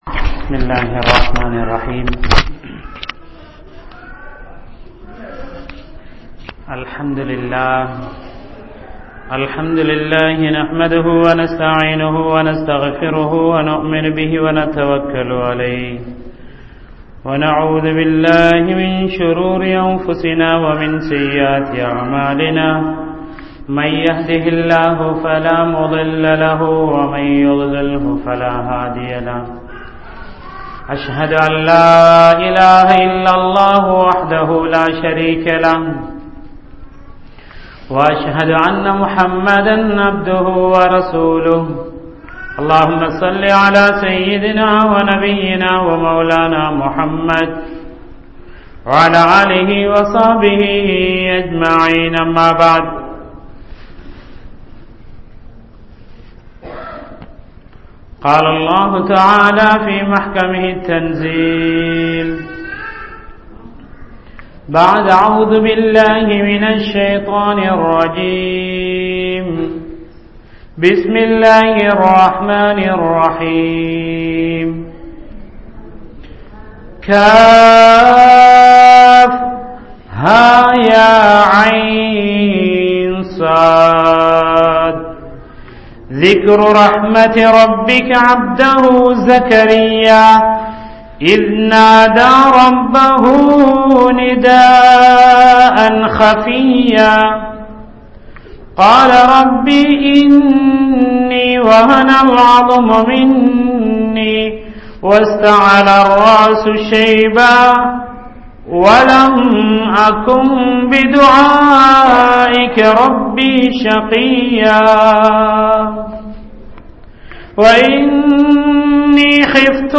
Allah`vin Atputham (அல்லாஹ்வின் அற்புதம்) | Audio Bayans | All Ceylon Muslim Youth Community | Addalaichenai